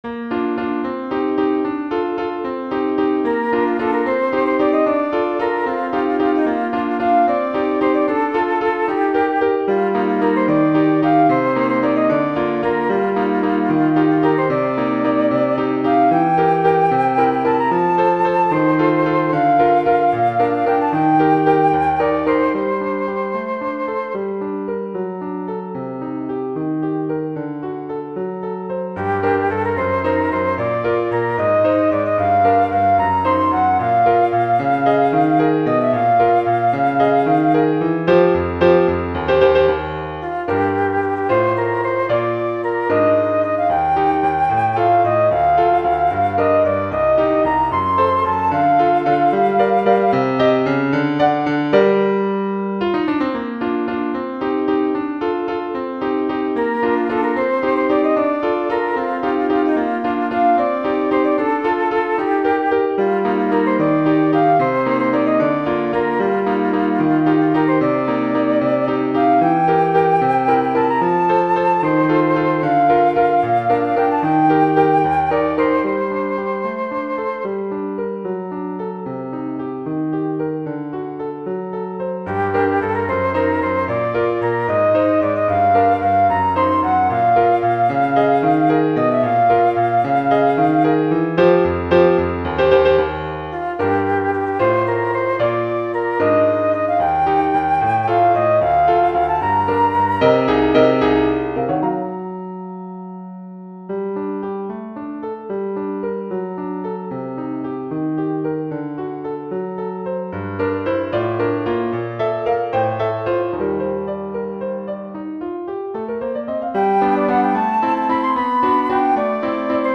OuĂ© le rendu est plutot pas mal, mĂŞme si Ă§a manque un peu d'audace Ă  mon goĂ»t.